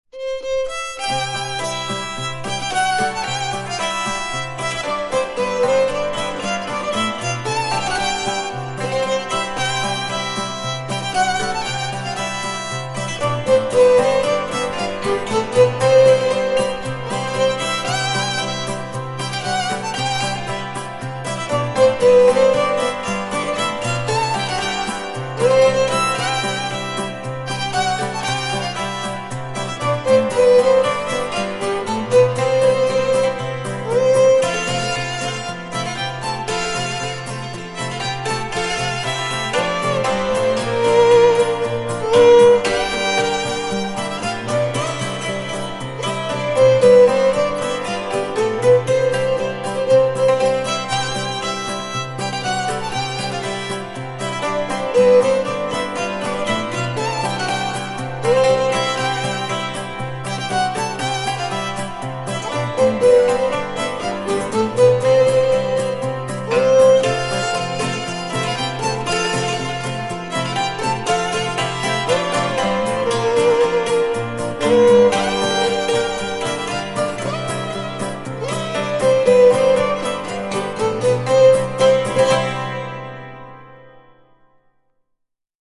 ΤΡΑΓΟΥΔΙΑ ΚΑΙ ΣΚΟΠΟΙ ΑΠΟ ΤΑ ΔΩΔΕΚΑΝΗΣΑ